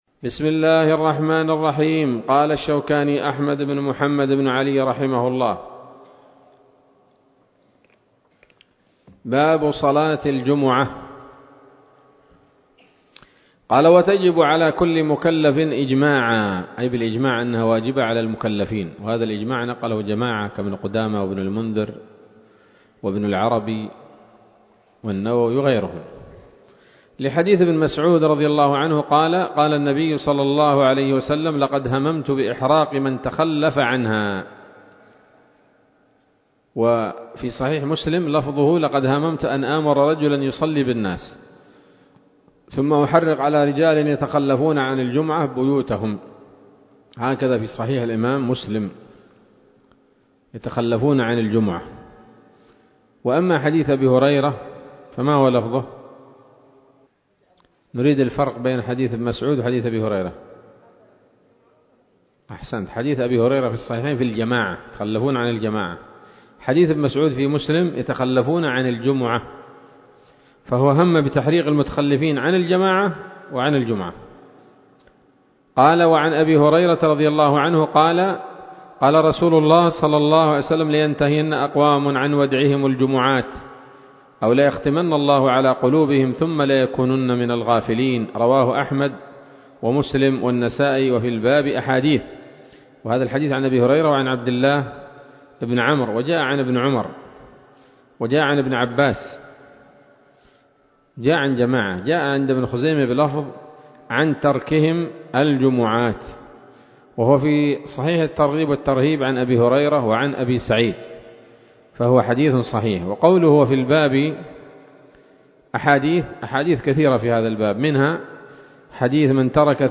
الدرس التاسع والثلاثون من كتاب الصلاة من السموط الذهبية الحاوية للدرر البهية